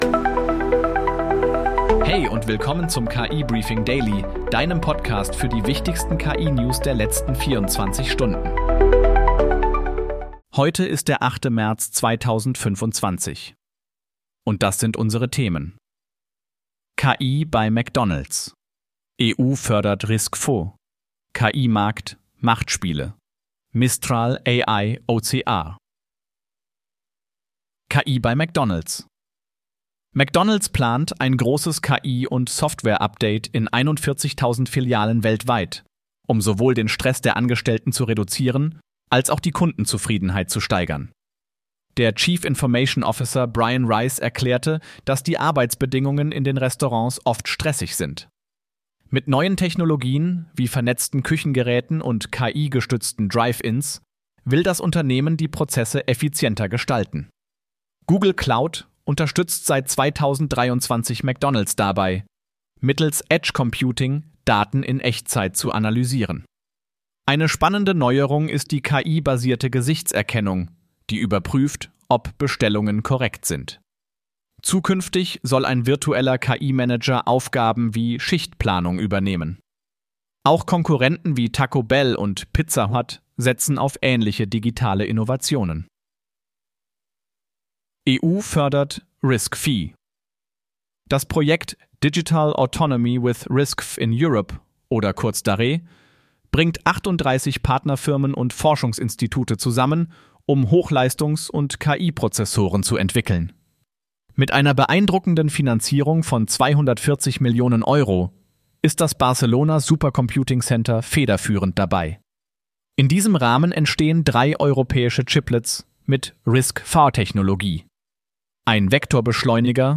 Möchtest du selbst einen solchen KI-generierten und 100% automatisierten Podcast zu deinem Thema haben?